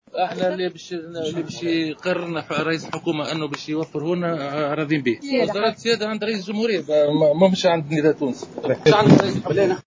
وشدد في تصريح للصحفيين على أن الحركة ستحترم قرارات رئيس الحكومة ومن يختاره من النداء لتولي حقائب وزارية في الحكومة الجديدة، قائلا ان اختيار من سيتولى وزارات السيادة " يعود الى رئيس الجمهورية وليس لنداء تونس أو رئيس الحكومة".